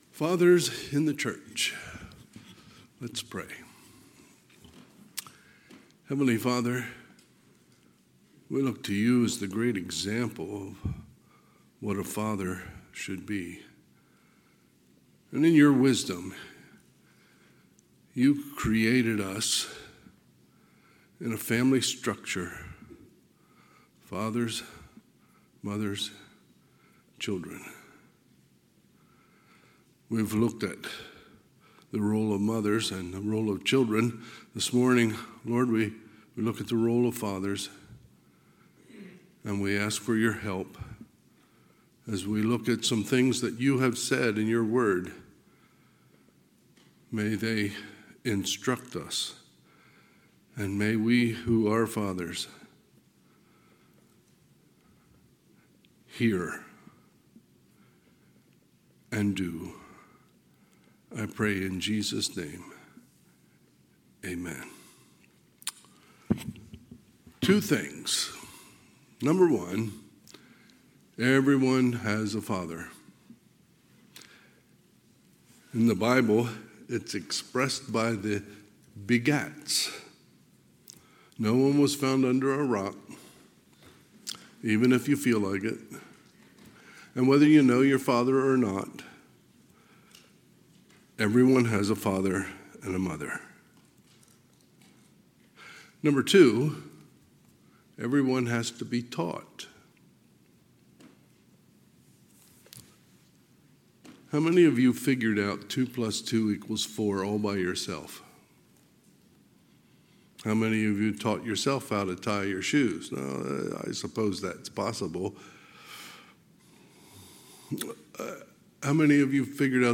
Sunday, June 16, 2024 – Sunday AM